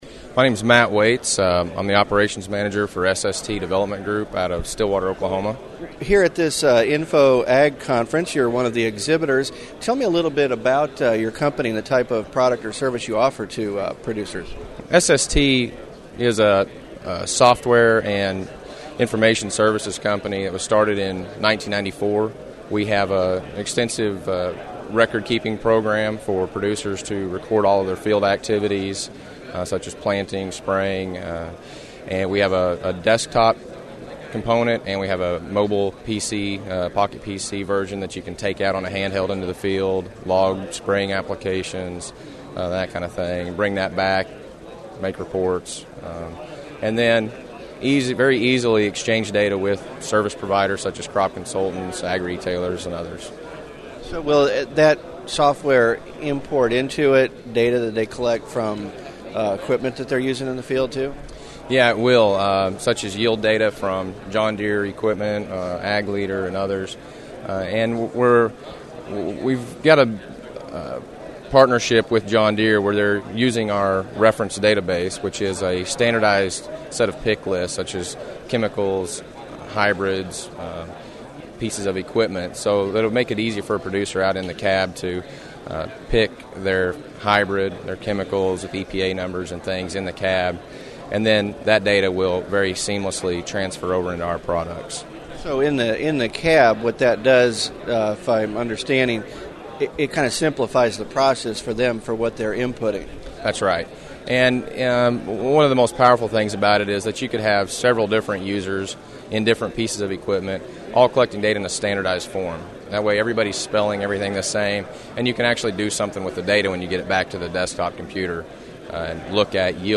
InfoAg 2007
SST Software Interview